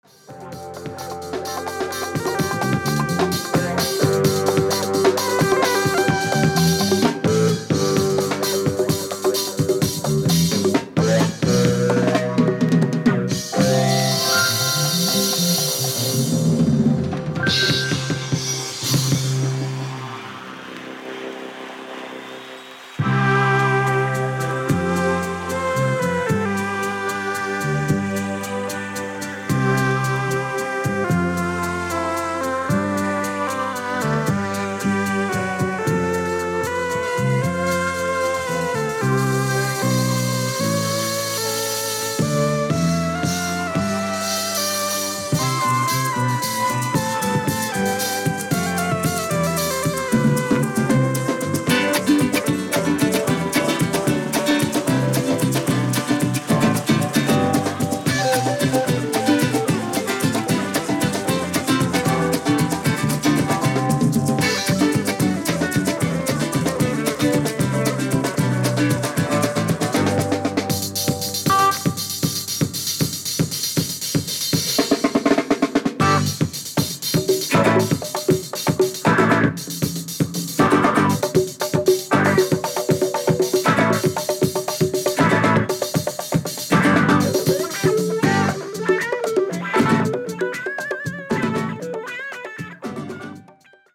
2枚組ライブ盤です。